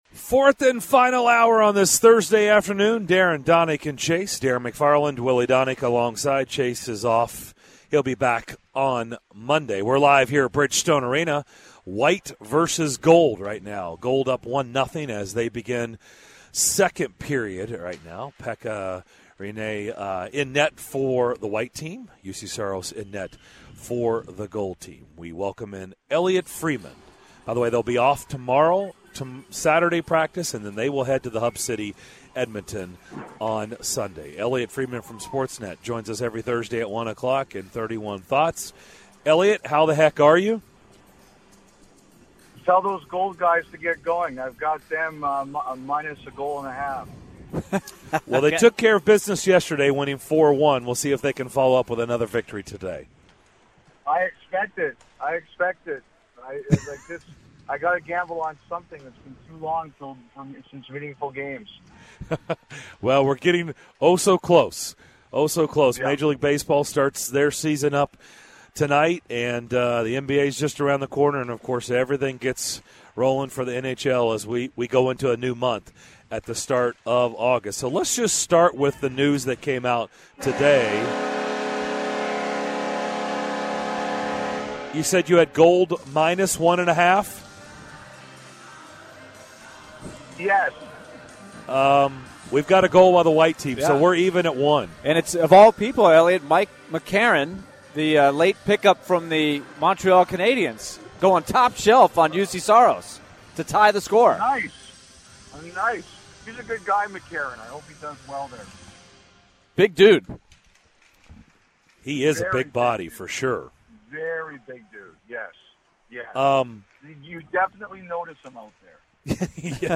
In the final hour of Thursday's DDC: the guys talk to Sportsnet's Elliotte Friedman, discuss the news of Juan Soto's positive COVID test and more.